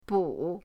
bu3.mp3